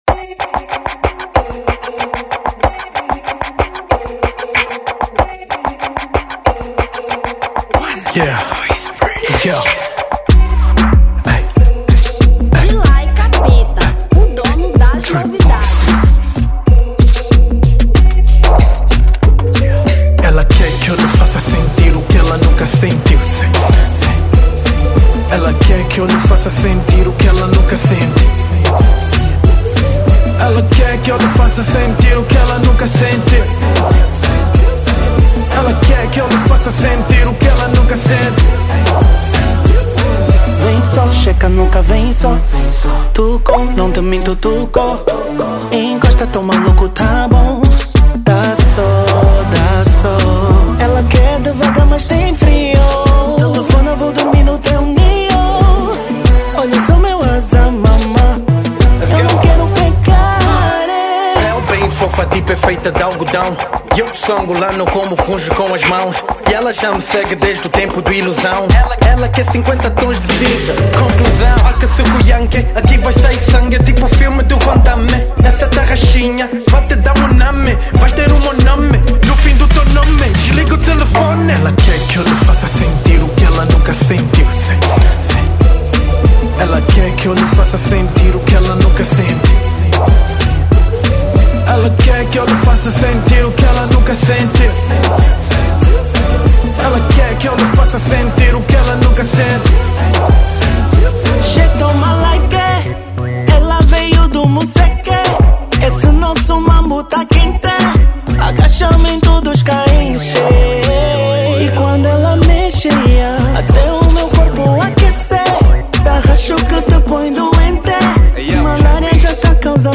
Tarraxinha 2025